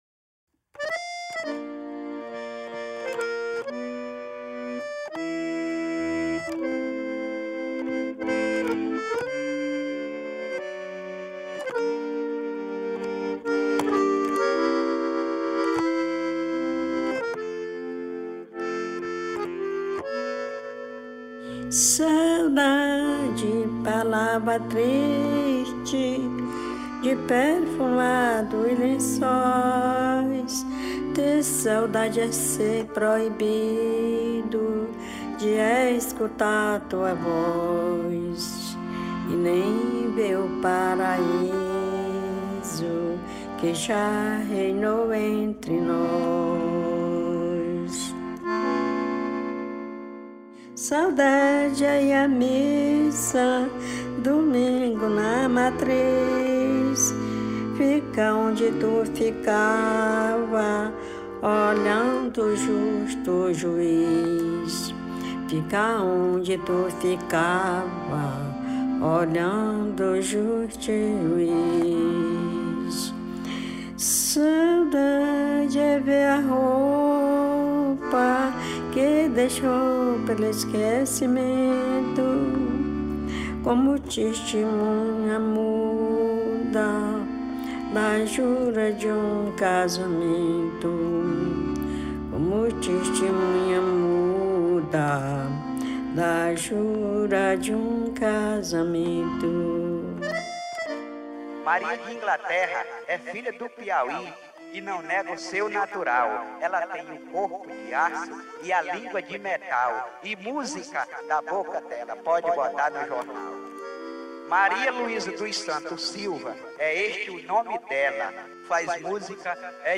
1244   02:35:00   Faixa: 0    Toada